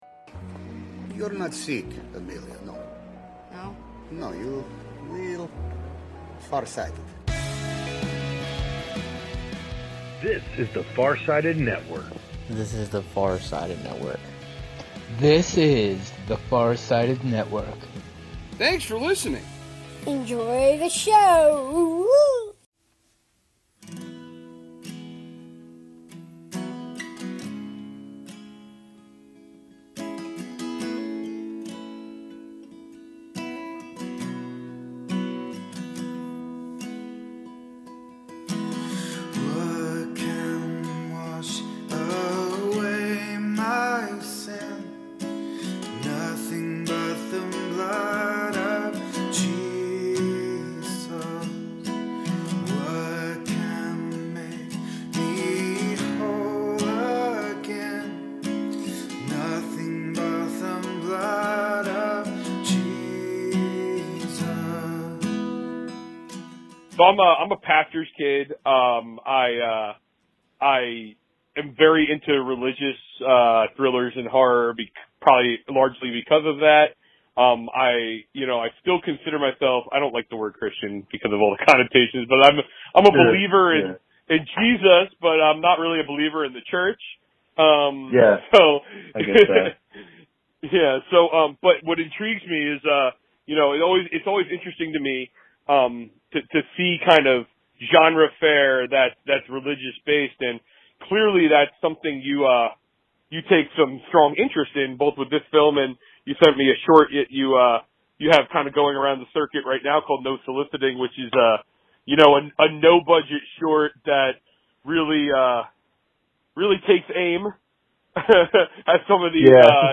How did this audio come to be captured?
The rebranded pod features raw and (mostly) unedited interviews, both new and not-so-new.